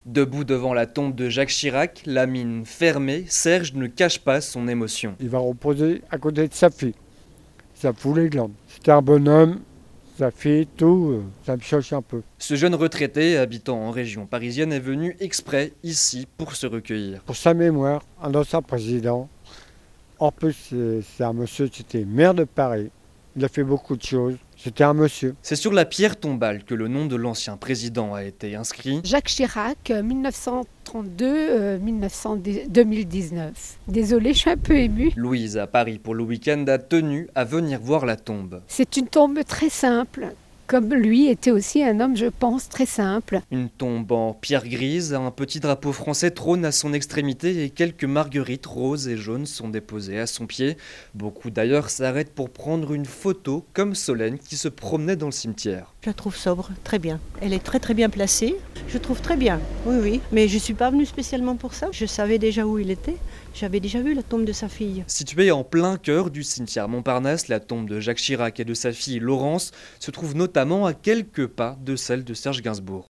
Des passants devant sa future tombe à Montparnasse